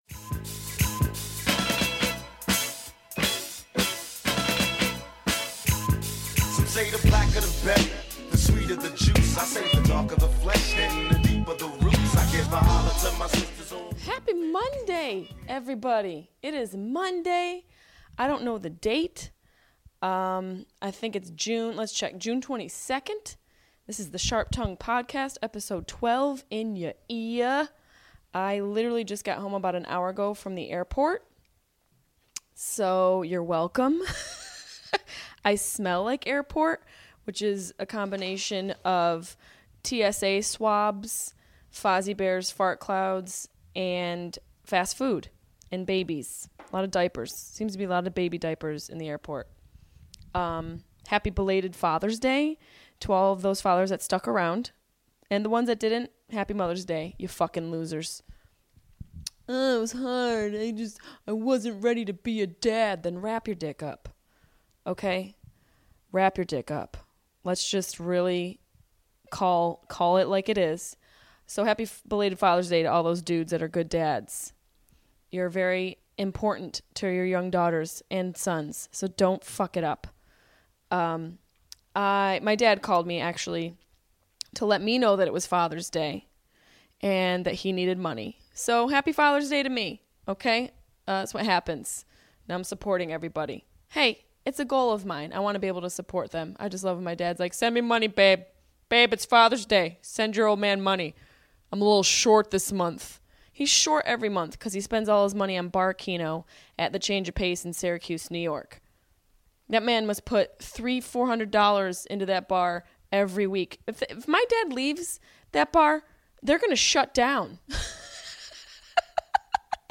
"10 Crack Commandments" We discuss selling crack, getting out of "the life", and his fears about being a father to daughters in today's society. With guest, radio host, Charlamagne Tha God.